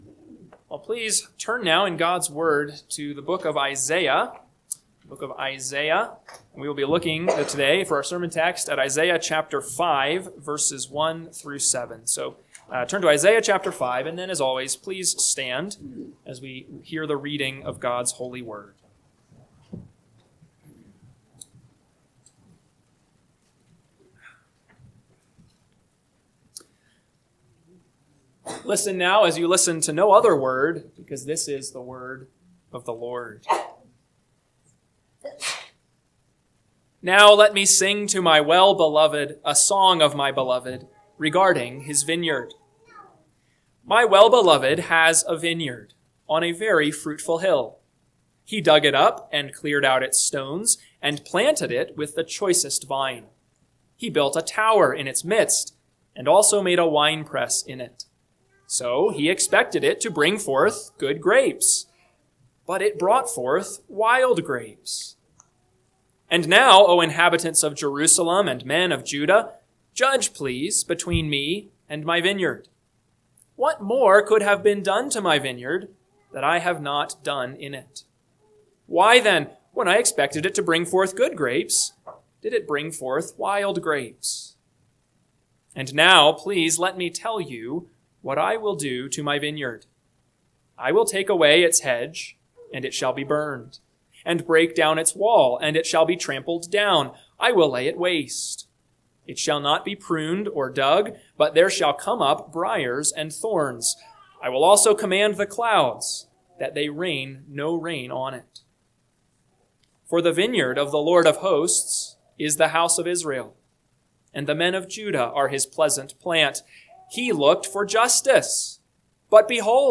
AM Sermon – 11/16/2025 – Isaiah 5:1-7 – Northwoods Sermons